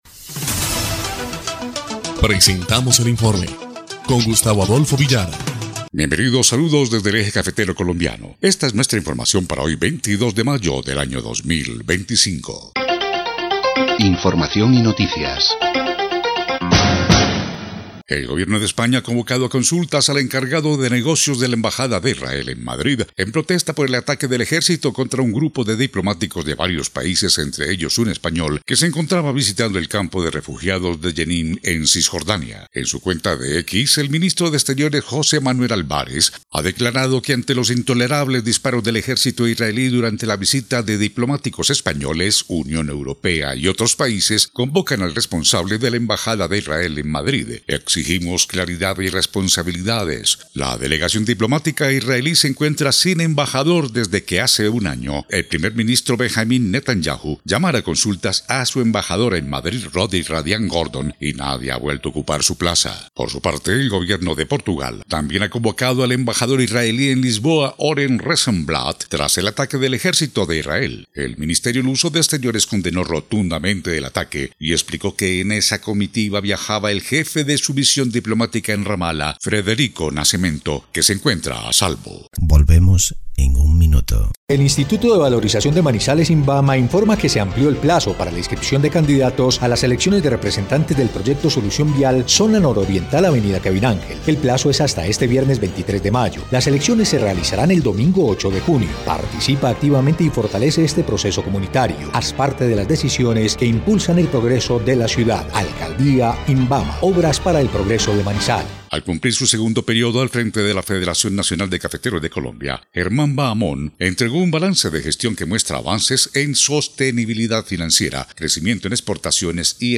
EL INFORME 2° Clip de Noticias del 22 de mayo de 2025